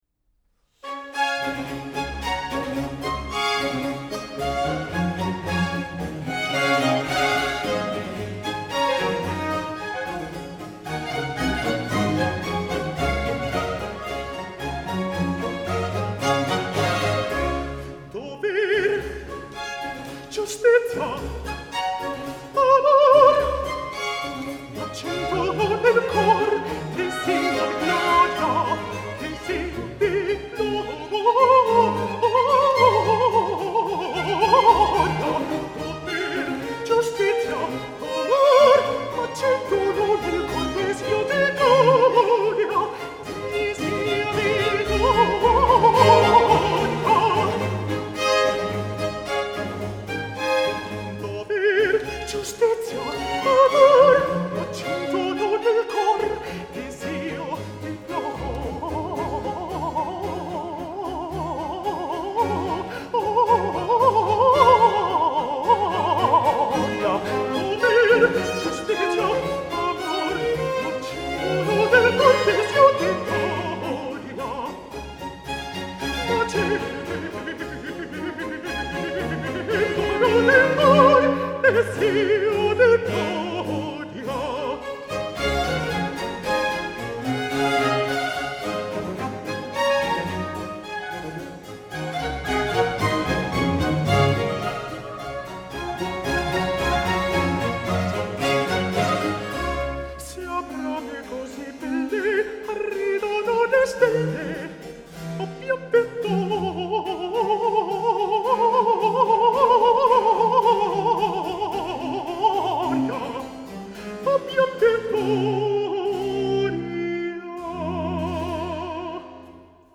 Countertenor Xavier Sabata presents Bad Guys: a programme of arias sung by villains in operas by Handel. Each great hero needs an antagonist, and often Handel’s ‘Bad Boys’ get to sing the best arias Accompanying Xavier Sabata in these concerts is Il Pomo d’Oro.